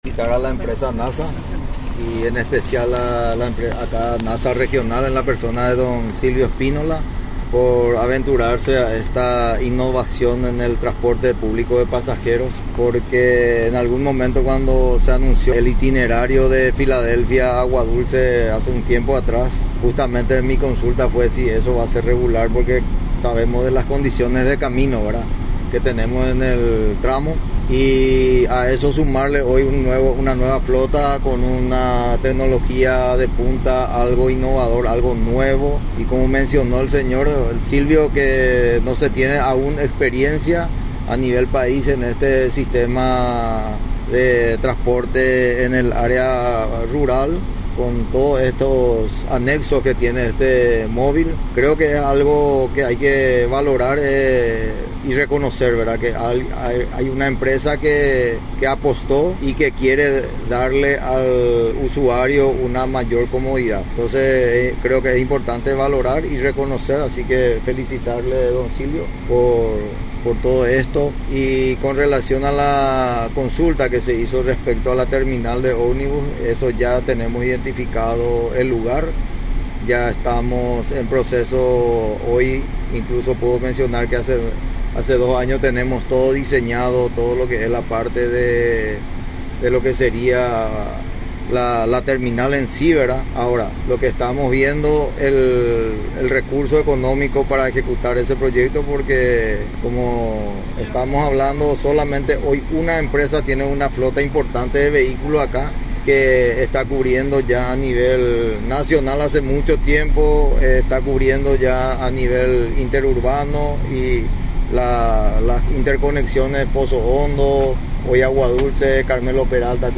Entrevistas / Matinal 610
Entrevistado: Claudelino Rodas
Estudio Central, Filadelfia, Dep. Boquerón